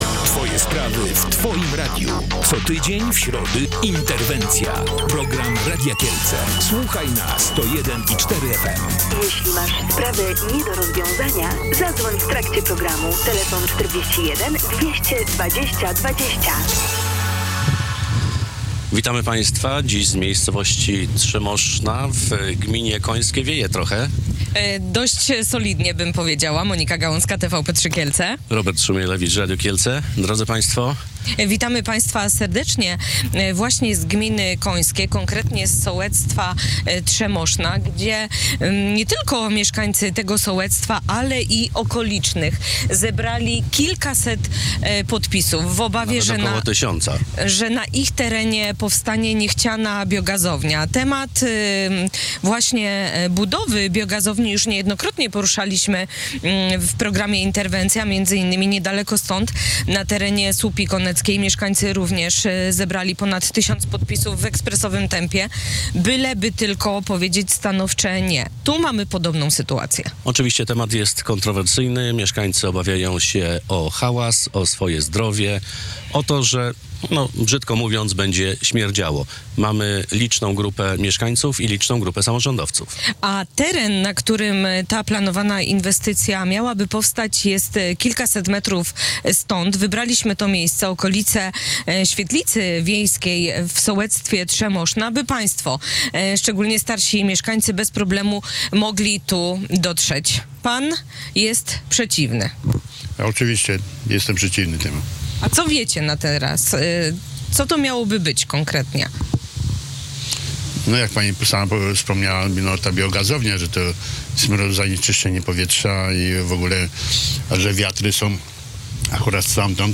Temu tematowi był poświęcony środowy program Interwencja, w którym uczestniczyło kilkudziesięciu mieszkańców sołectw: Trzemoszna, Bedlno, Sworzyce, Pomorzany i Przybyszowy.
Udział w nim wzięli również przedstawiciele władz samorządowych – burmistrz gminy Końskie Krzysztof Obratański i jego zastępca Marcin Zieliński, a także radni gminni Mariusz Łebek i Krzysztof Sota oraz sołtysi Dariusz Majewski i Henryk Dąbrowski.